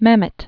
(mămĭt), David Born 1947.